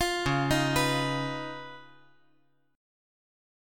C#9 Chord
Listen to C#9 strummed